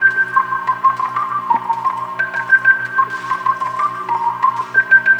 Back Alley Cat (Organ 01).wav